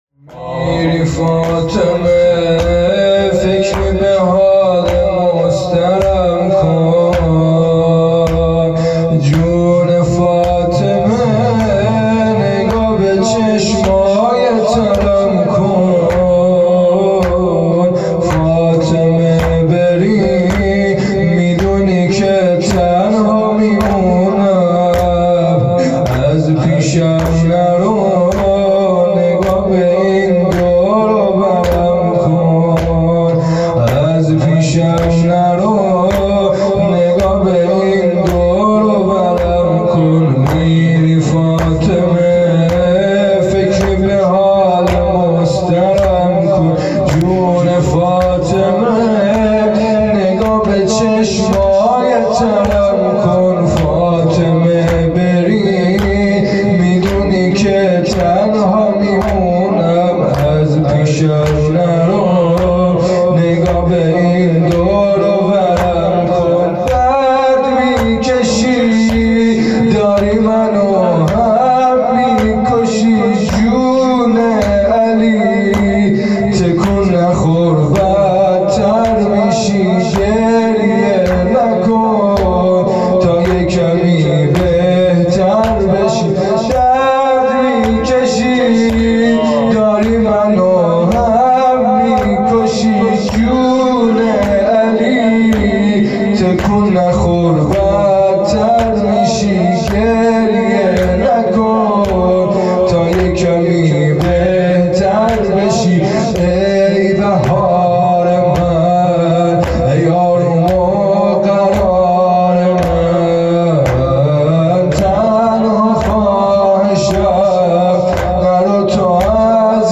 شب چهارم شهادت حضرت زهرا (س)
زمینه(میری فاطمه